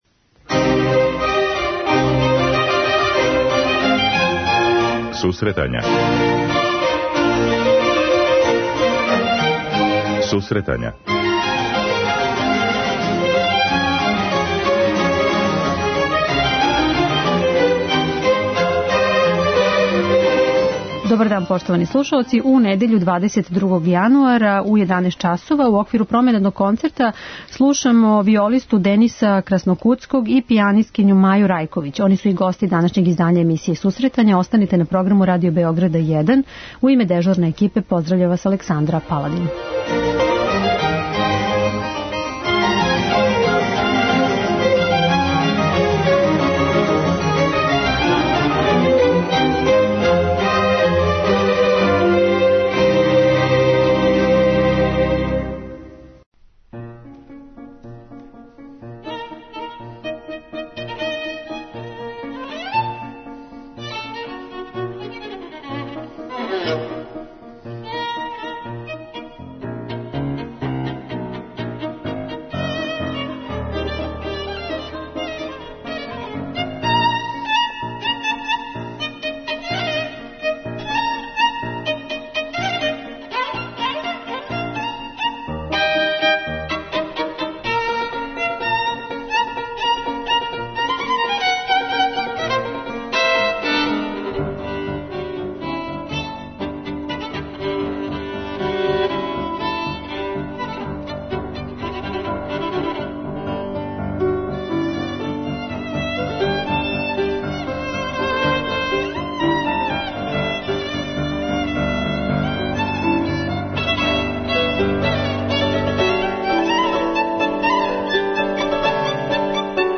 преузми : 10.68 MB Сусретања Autor: Музичка редакција Емисија за оне који воле уметничку музику.